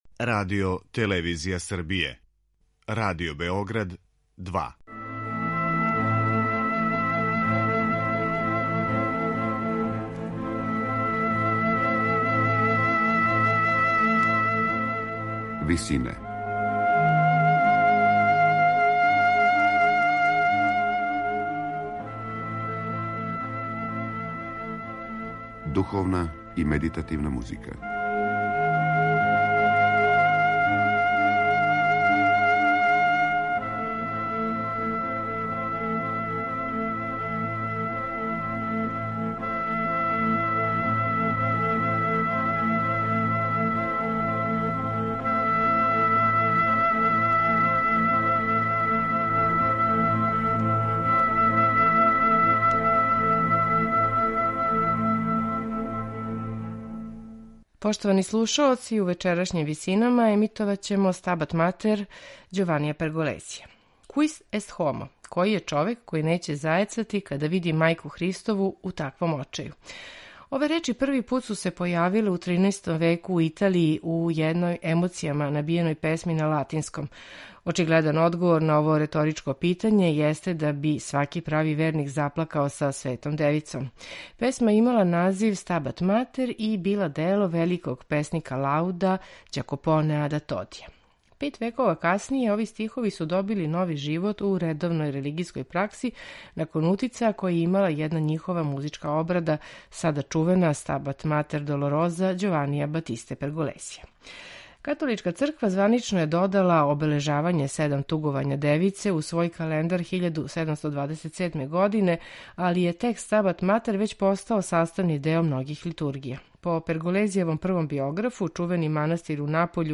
сопран
контратенор и ансамбл Concerto vocale